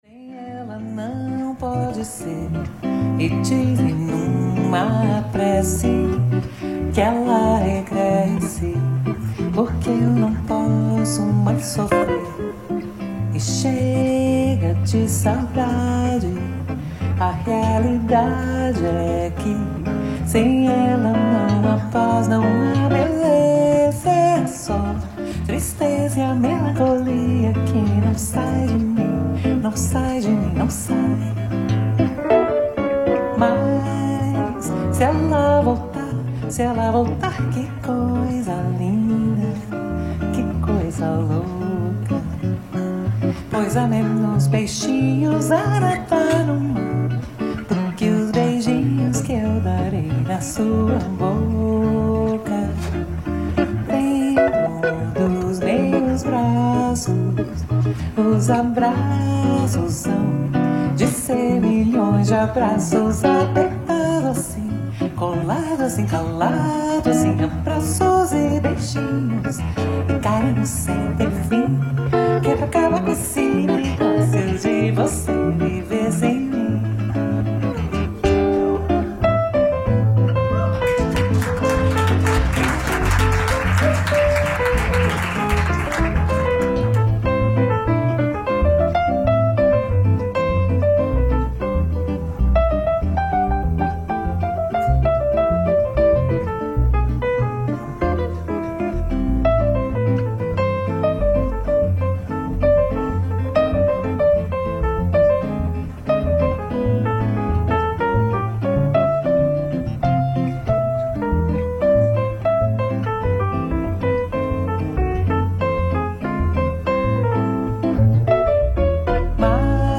ライブ・アット・ボストン、マサチューセッツ 04/11/2014
※試聴用に実際より音質を落としています。